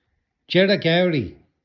1. черекэури